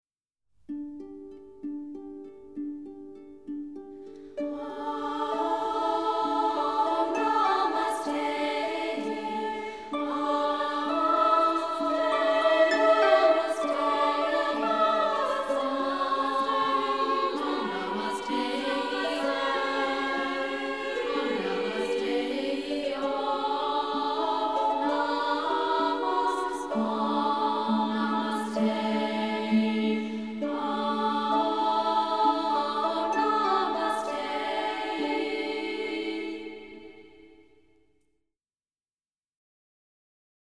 full of heartfelt themes, lilting waltzes, Indian flourishes